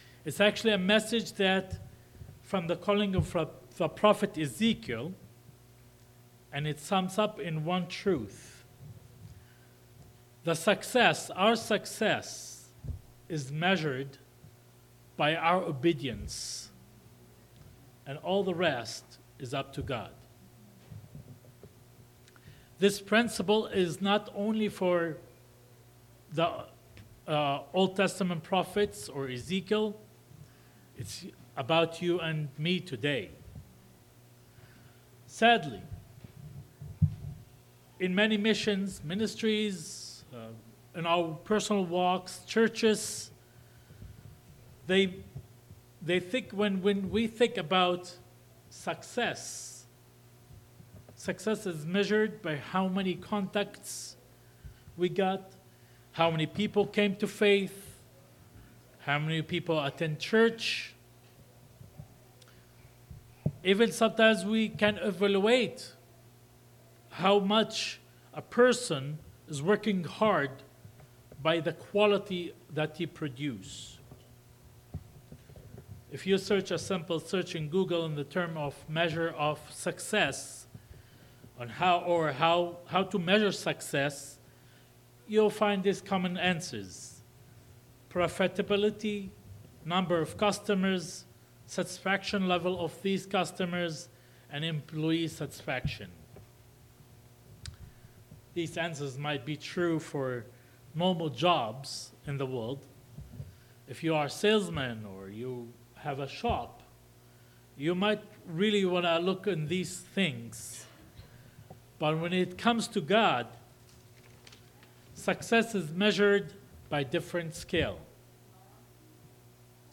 July-6-2025-Morning-Service.mp3